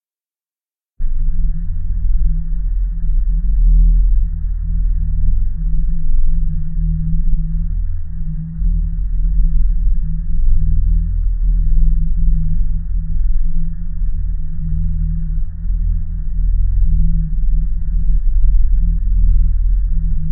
Weird ambiance - magical reasonance.ogg